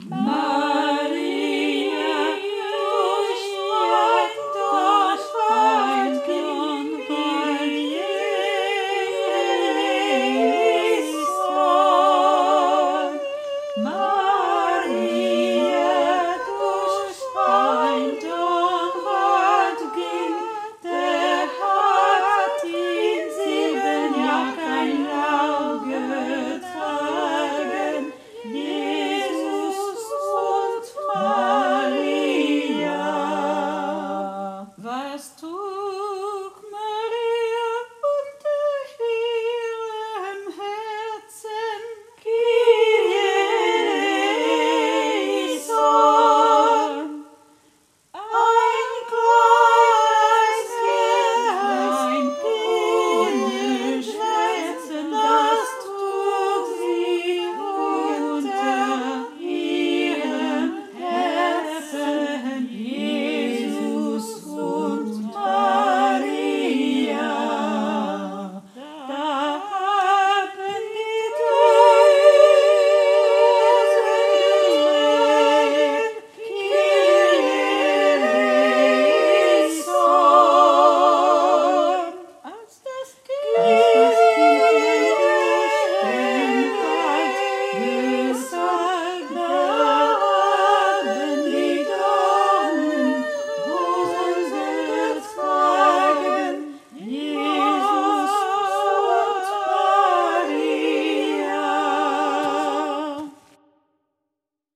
toutes les voix